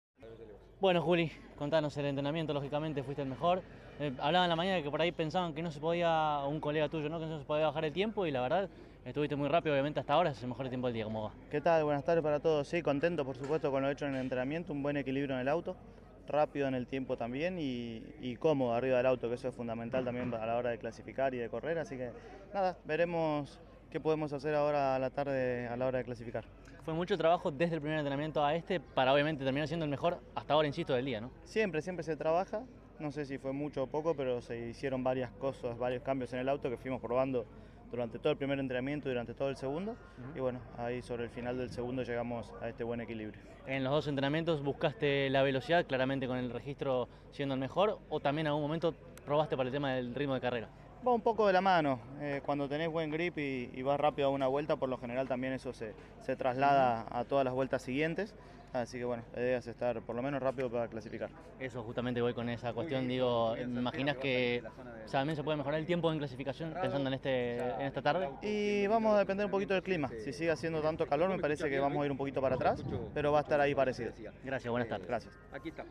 El testimonio de Santero, en diálogo con CÓRDOBA COMPETICIÓN: